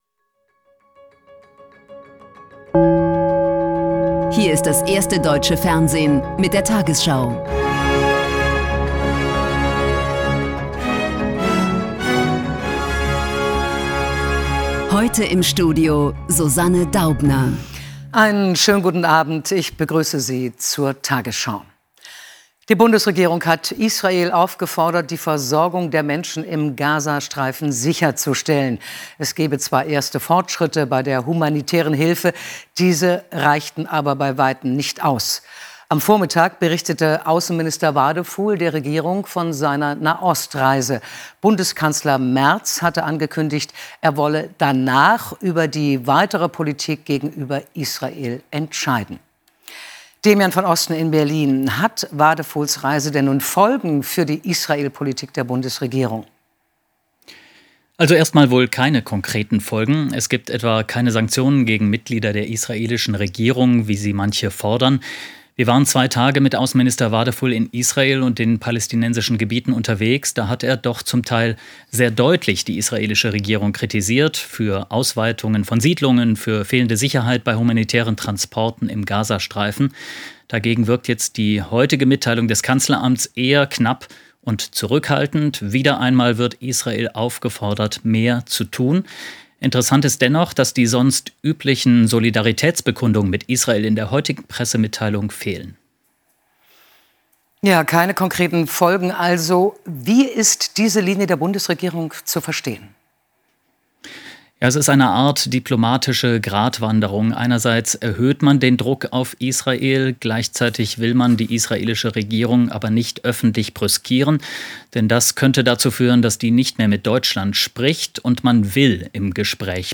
Die 20 Uhr Nachrichten von heute zum Nachhören. Hier findet ihr immer die aktuellsten und wichtigsten News.